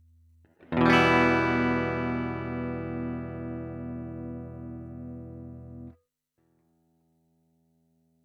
I’m having a hard time differentiating between my Laney TT50 (tube amp) and the SY-1000 amp models.
Using the LANEY TT50 CLEAN. TT50 CLEAN AMP